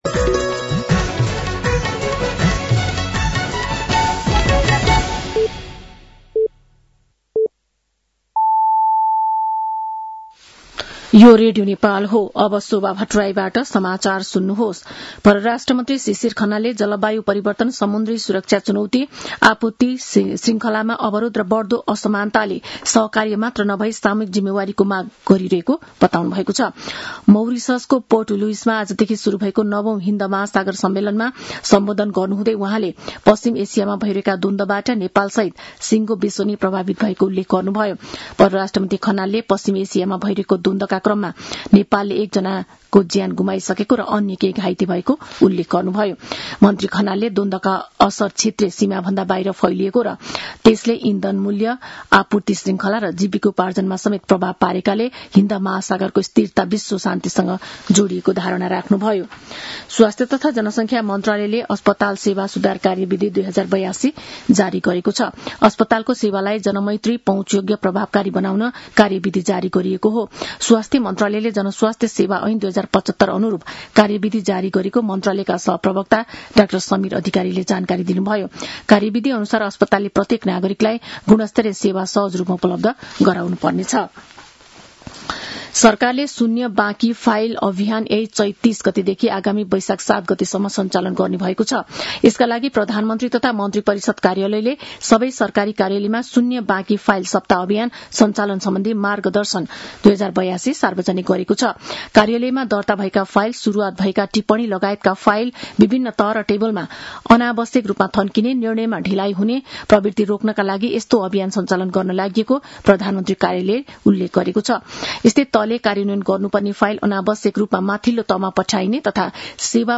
साँझ ५ बजेको नेपाली समाचार : २८ चैत , २०८२
5.-pm-nepali-news-1-3.mp3